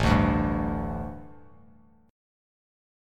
A7sus4 chord